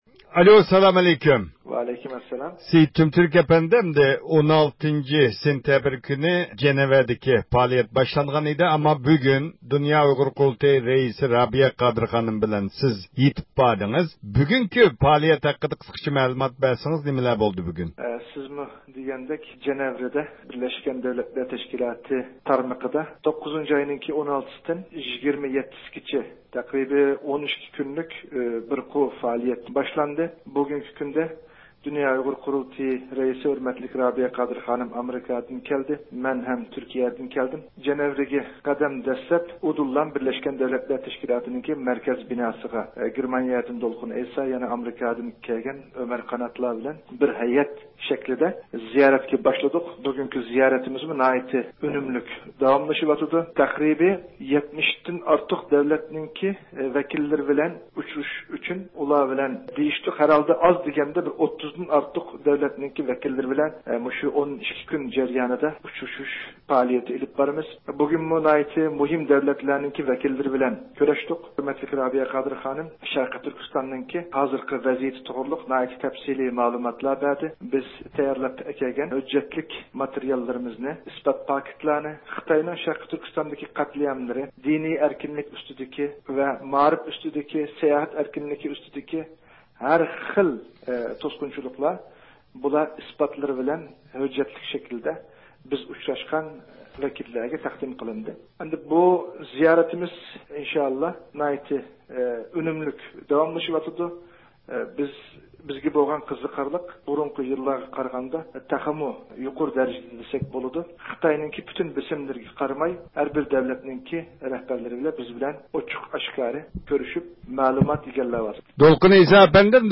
مەزكۇر ھەيئەت 18-سېنتەبىر كۈنى ناھايىتى مۇھىم ئۇچرىشىشلارنى ئۆتكۈزگەن بولۇپ، بۇ ھەقتە مەلۇمات ئېلىش ئۈچۈن ئۇلار بىلەن تېلېفون سۆھبىتى ئېلىپ باردۇق.